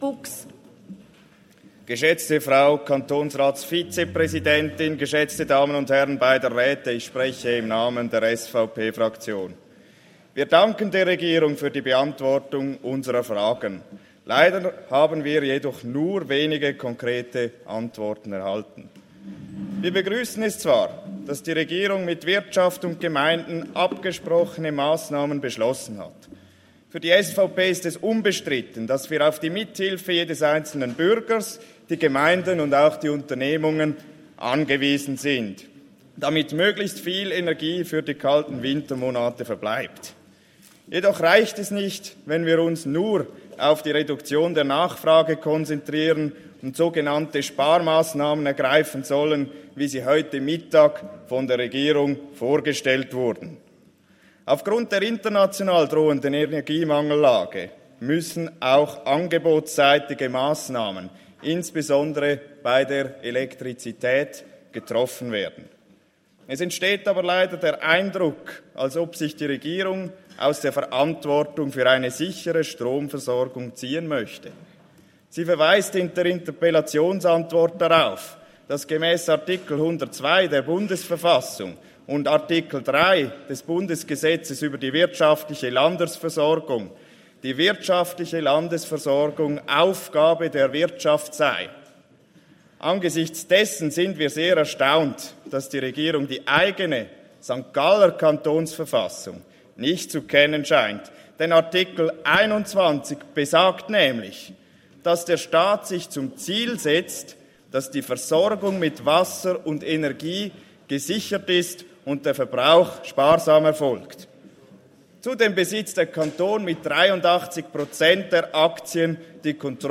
Schmid-Buchs (im Namen der SVP-Fraktion): Die Interpellantin ist mit der Antwort der Regierung nicht zufrieden.